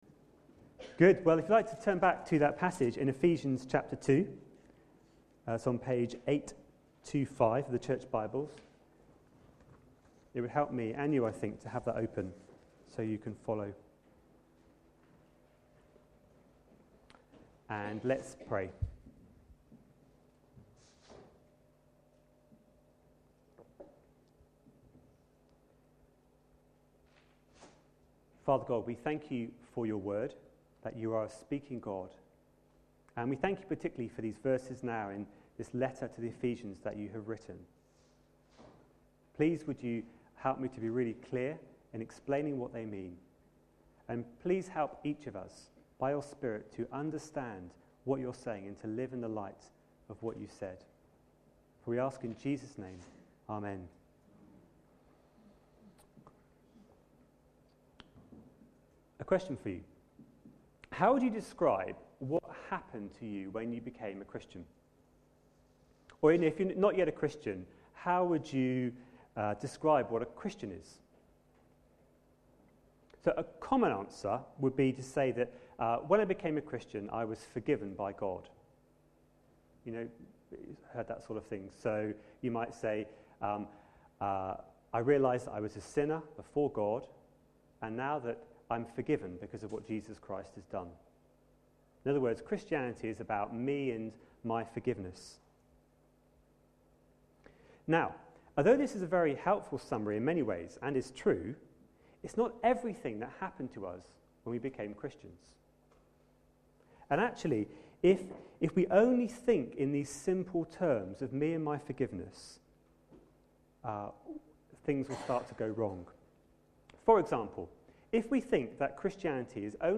A sermon preached on 30th January, 2011, as part of our Ephesians series.